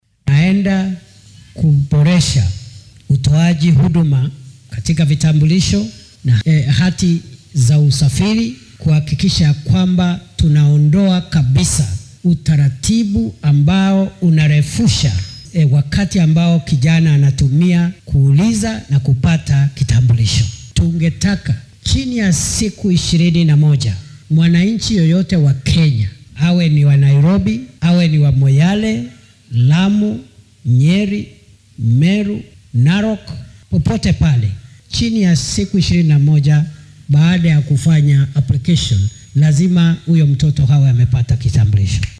Wasiirka wasaaradda arrimaha gudaha iyo maamulka qaran Prof, Kithure Kindiki ayaa sheegay inay qasab tahay in shaqaalaha waaxda qaran ee diiwaangelinta muwaadiniinta ay muddo saddex toddobaad gudahooda ah ku soo saaraan codsiyada la xiriira aqoonsiga qaran. Arrintan ayuu ka sheegay ismaamulka Lamu oo uu maalinkii labaad ku sugan yahay isagoo halkaasi kulamo kula yeelanaya qaybaha kala duwan ee bulshada si loo xoojiyo amniga. Kindiki ayaa carrabka ku adkeeyay in la doonaya in meesha laga saaro caqabadaha iyo habraacyada muddada dheer qaata ee saameeya dadka codsado aqoonsiga qaran.